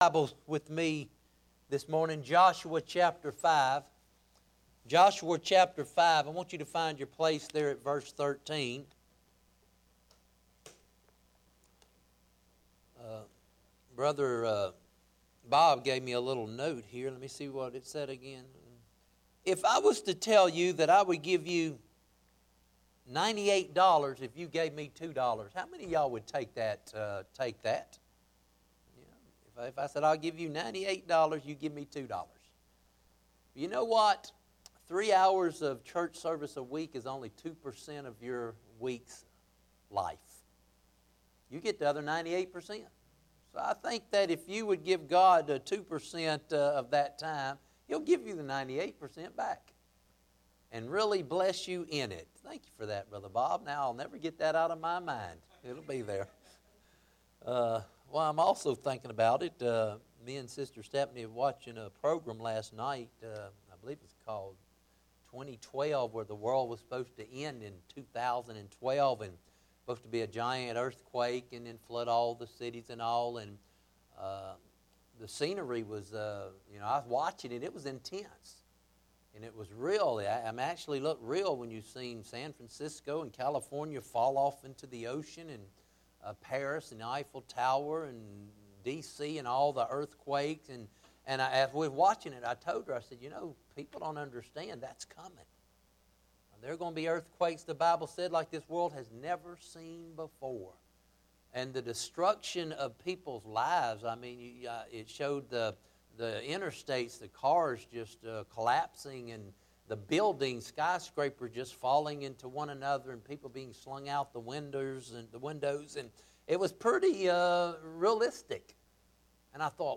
Bible Text: Joshua 5:13-15; 6:1-10 | Preacher